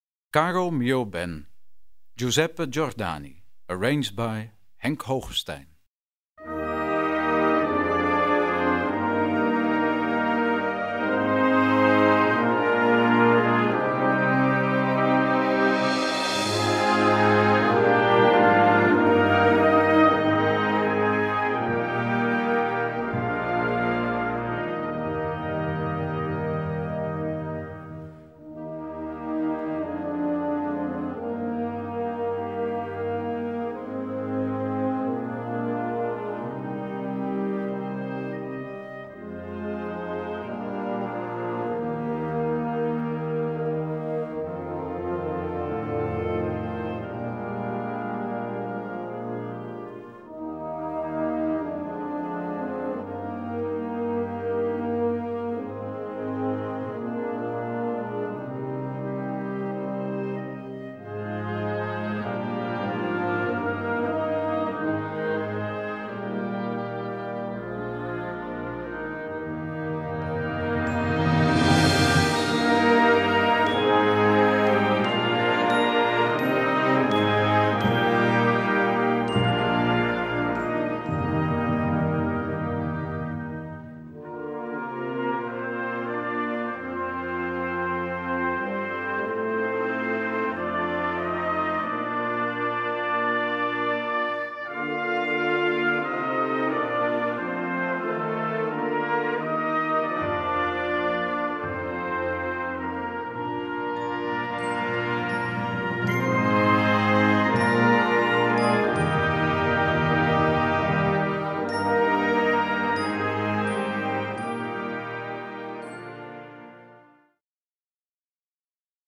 Diese schöne, lyrische Musik
Choral für Blasorchester Schwierigkeit
A4 Besetzung: Blasorchester Zu hören auf
ein stimmungsvolles Arrangement für Blasorchester.